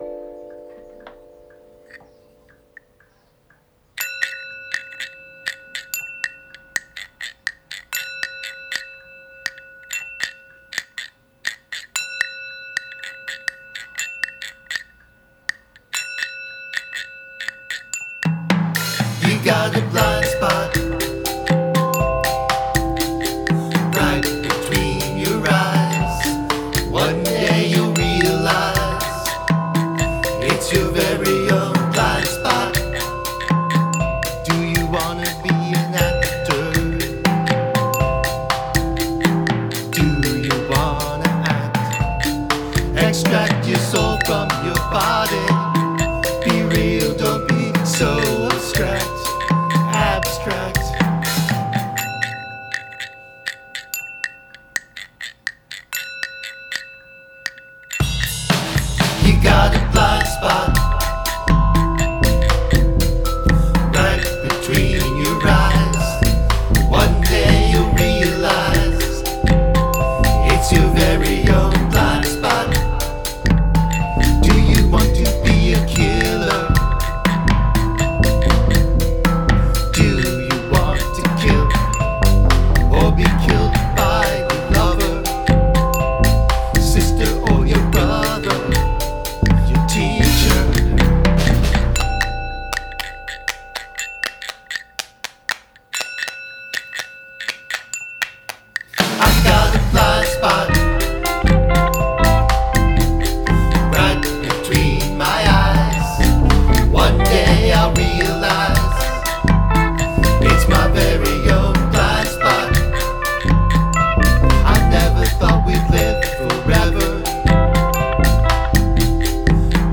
Hand percussion